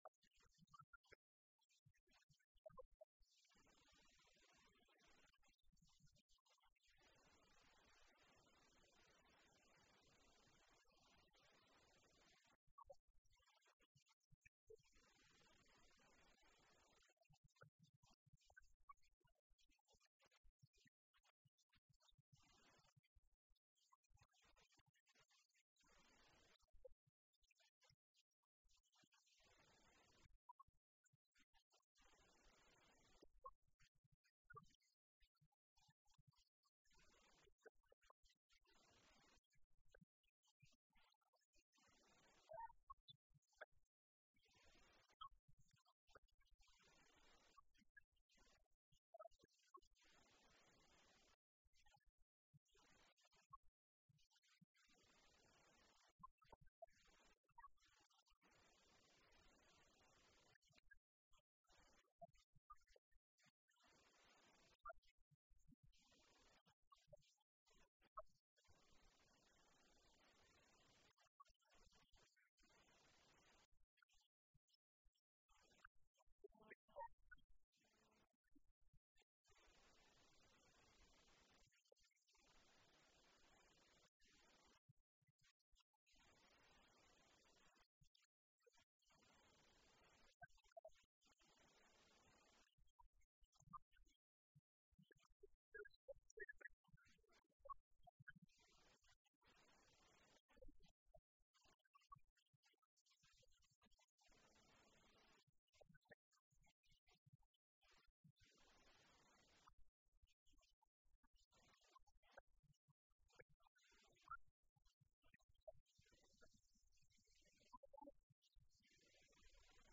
This sermon was given at the Italy 2015 Feast site.